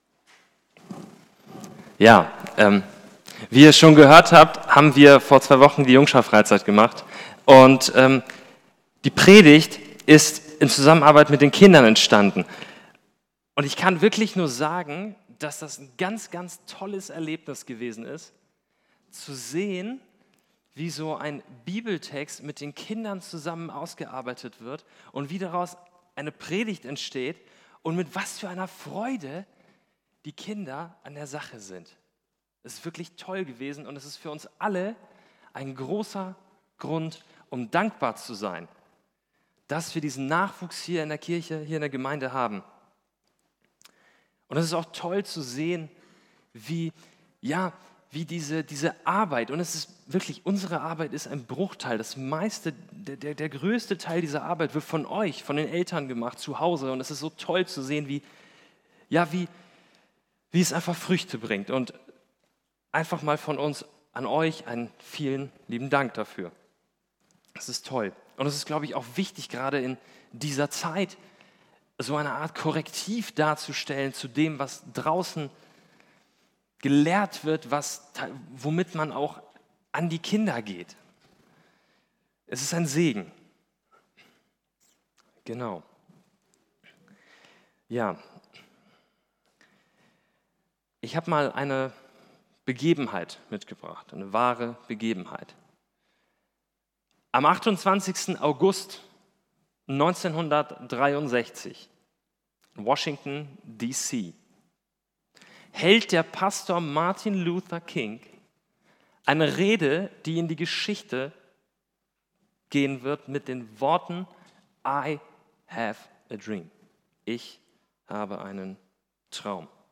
Predigt vom 02.07.2023